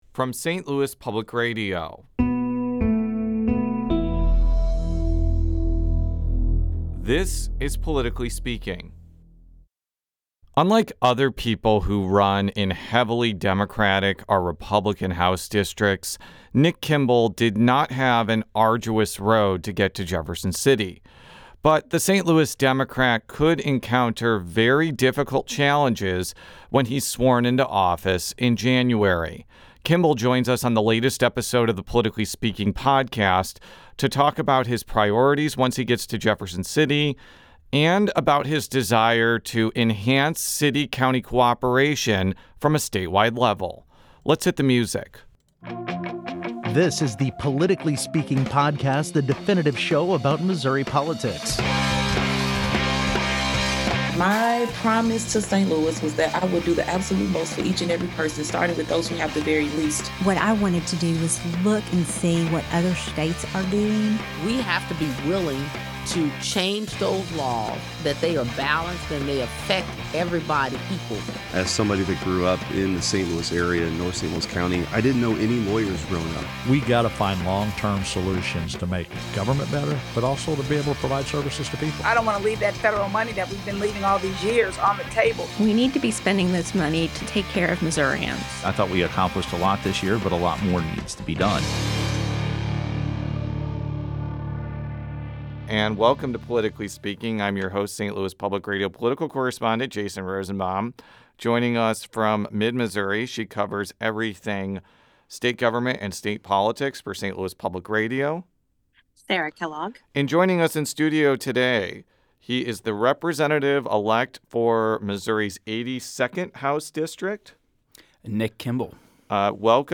Missouri's political news makers talk candidly